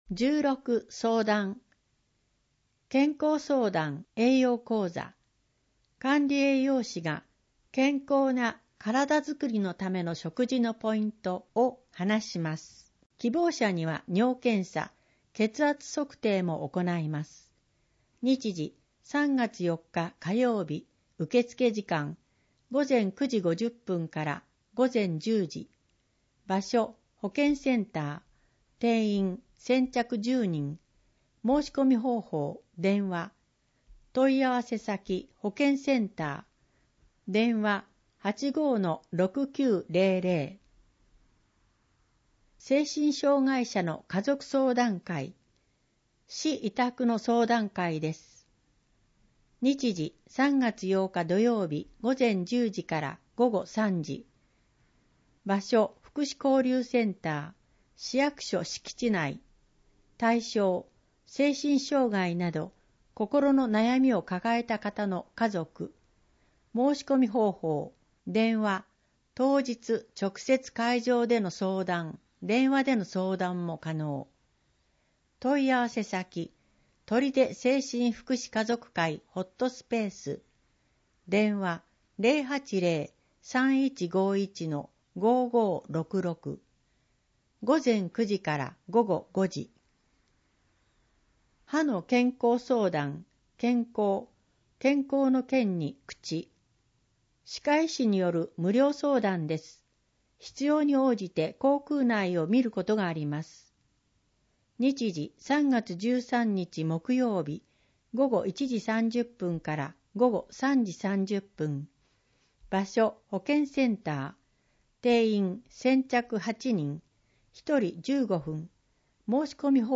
取手市の市報「広報とりで」2025年2月15日号の内容を音声で聞くことができます。音声データは市内のボランティア団体、取手朗読奉仕会「ぶんぶん」の皆さんのご協力により作成しています。